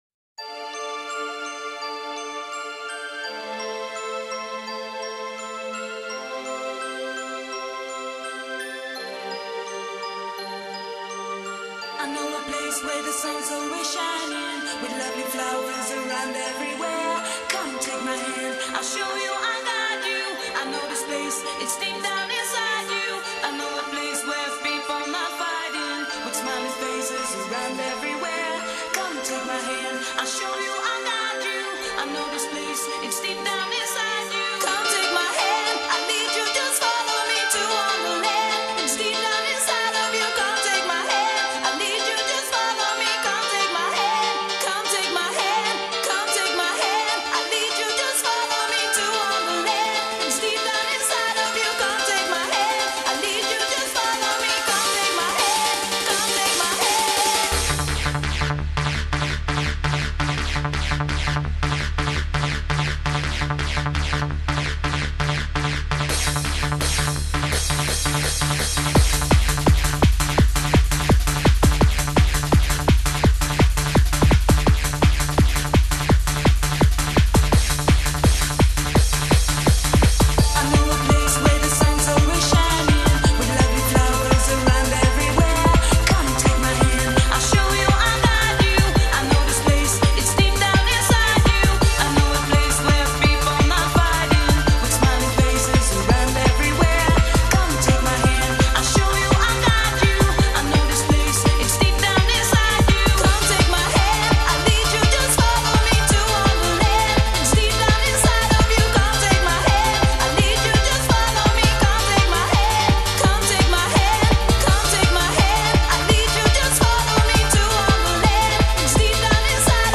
Назад в Exclusive EuroDance 90-х